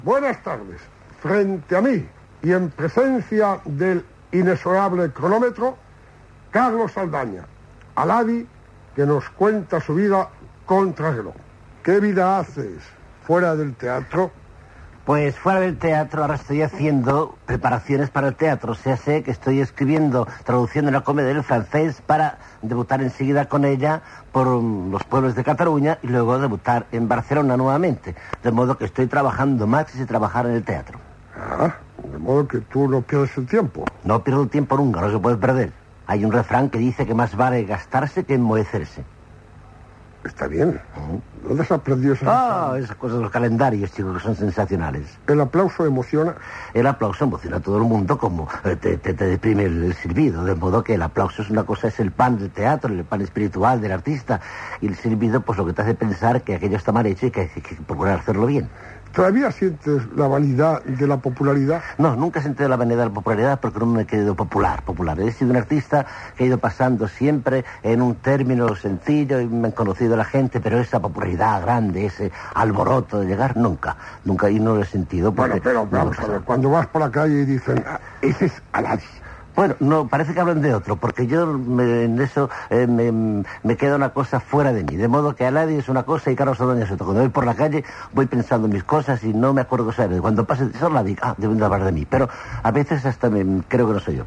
Entrevista a l'actor Carlos Saldaña "Alady"